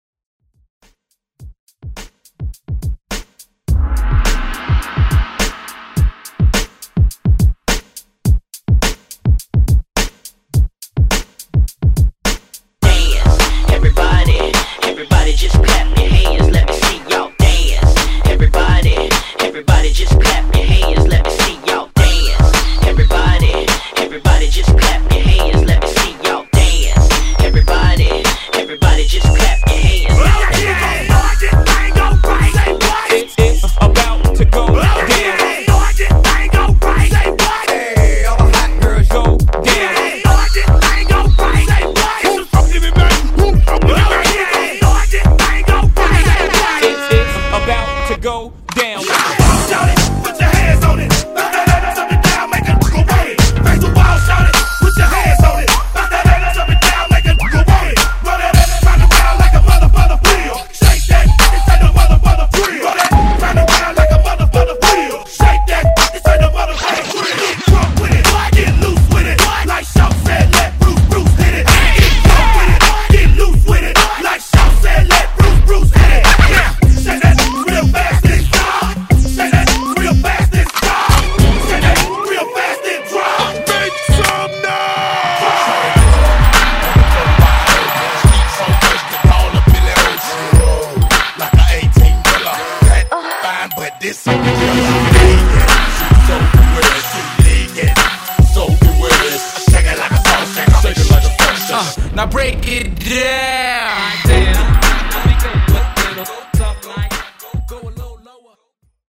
Genres: R & B , TOP40
Clean BPM: 104 Time